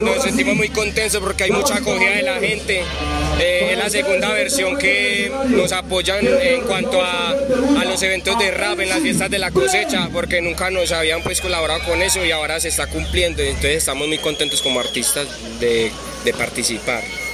ASISTENTE_AL_EVENTO.mp3